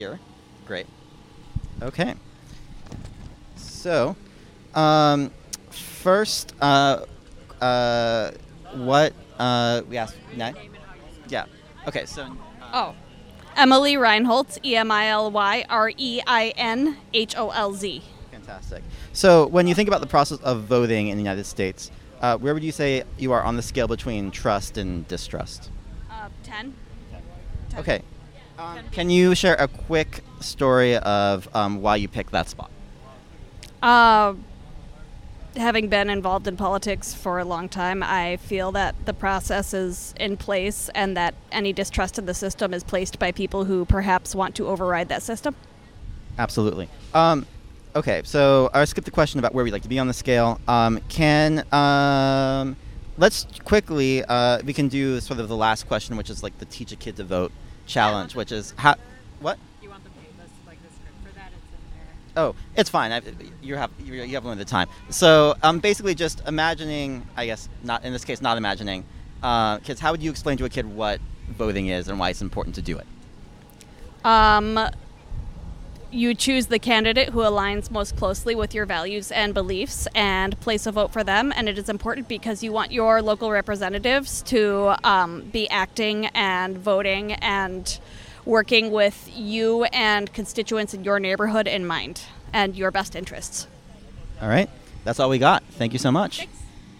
Jackson Park Farmer's Market